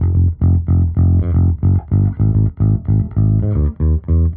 Index of /musicradar/dusty-funk-samples/Bass/110bpm
DF_JaBass_110-F.wav